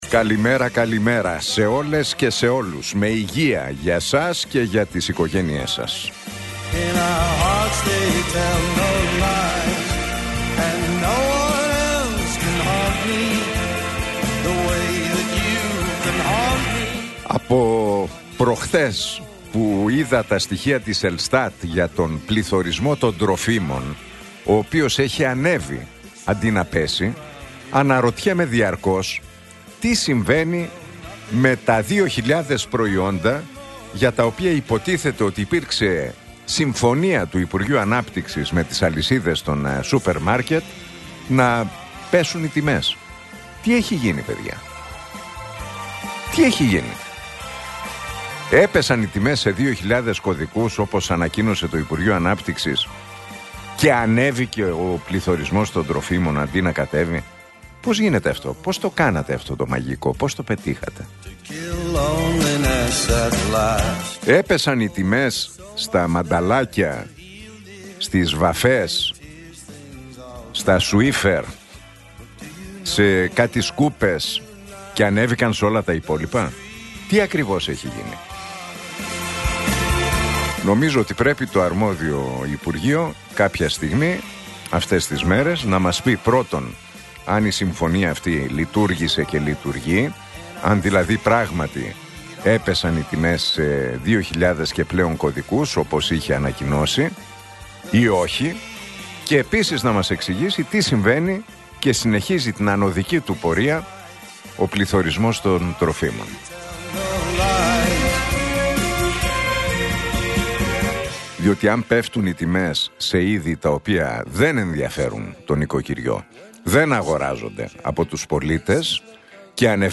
Ακούστε το σχόλιο του Νίκου Χατζηνικολάου στον ραδιοφωνικό σταθμό Realfm 97,8, την Πέμπτη 13 Νοεμβρίου 2025.